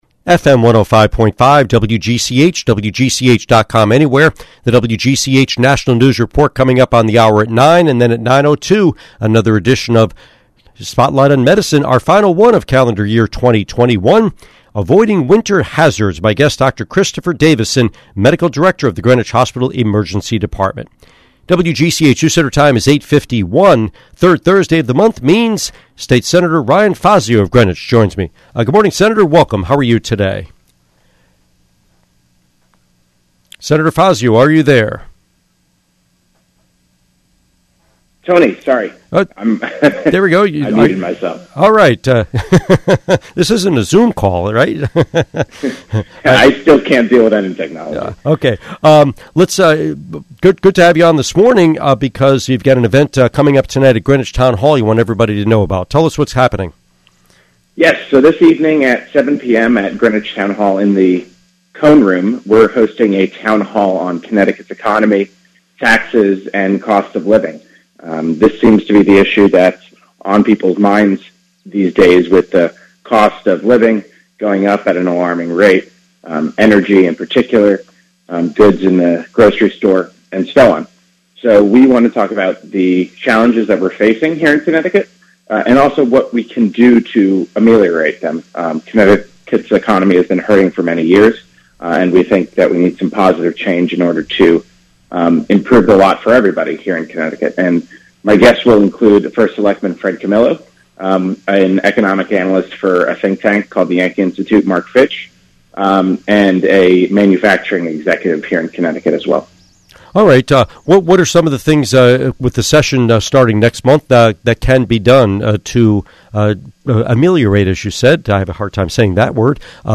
Interview with State Senator Fazio